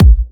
VEC3 Bassdrums Trance 30.wav